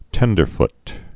(tĕndər-ft)